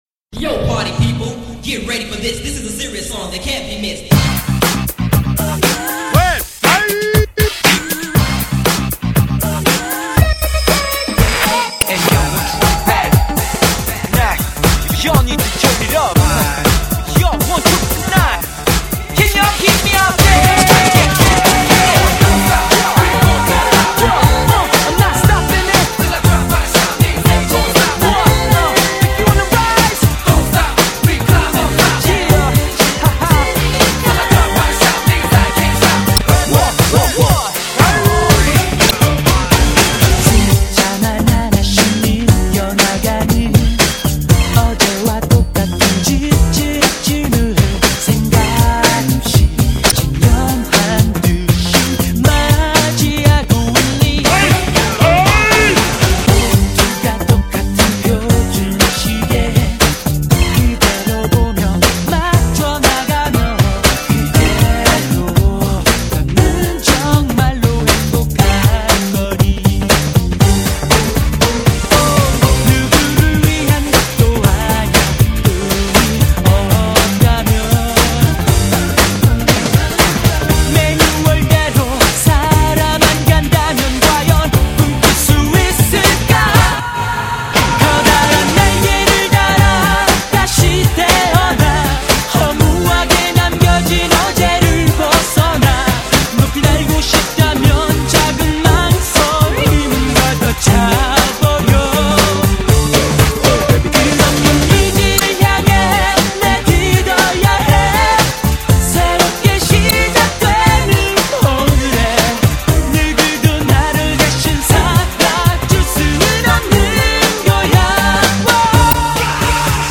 BPM178--1
Audio QualityPerfect (High Quality)